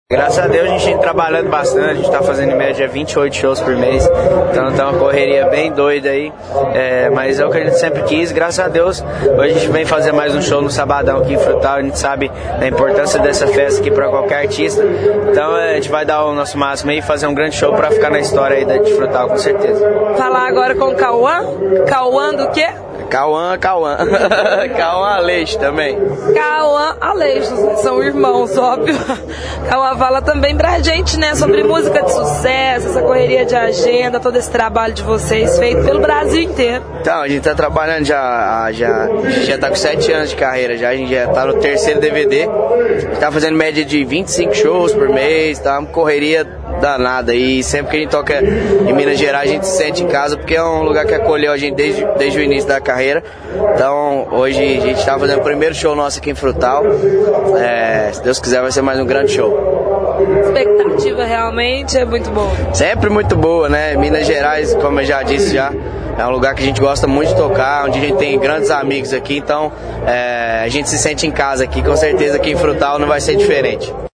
Os cantores Matheus e Kauan, falaram sobre a sua carreira e do sucesso que a dupla vem fazendo no Brasil.